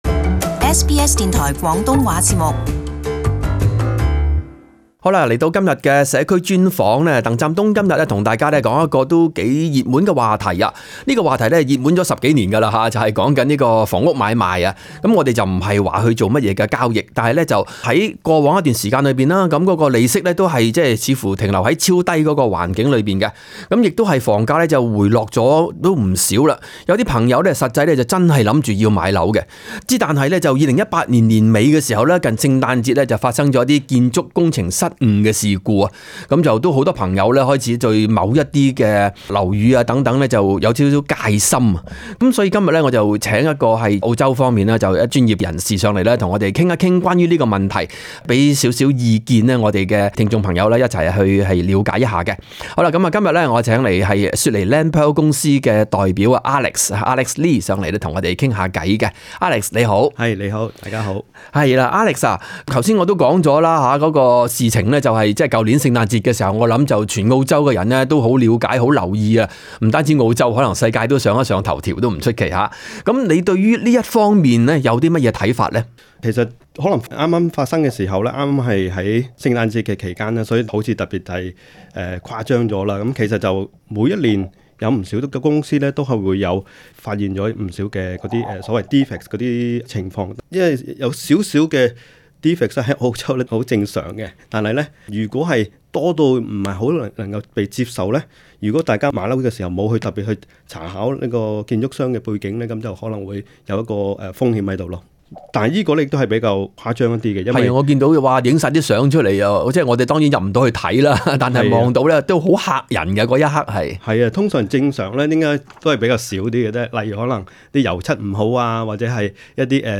【社區專訪】 樓宇工程瑕疵屬正常？普羅大眾凖買家該如何避受其害？